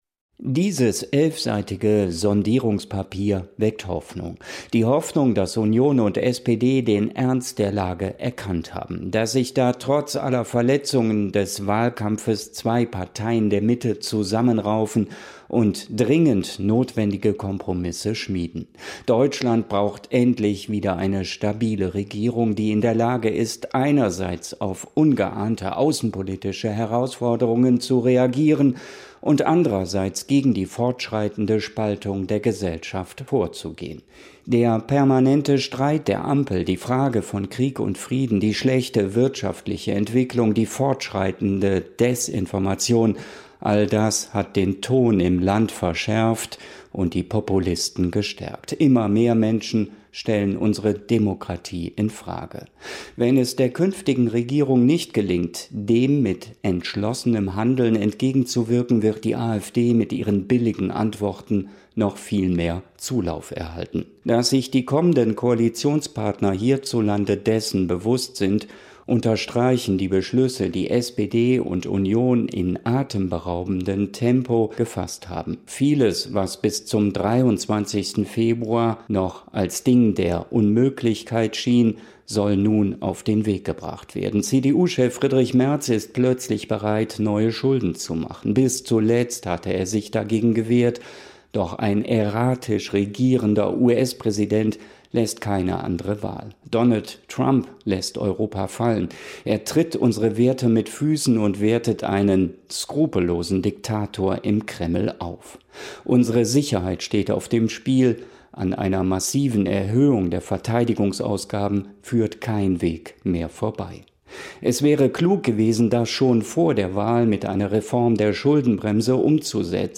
Kommentar: Sondierungen die Hoffnung wecken!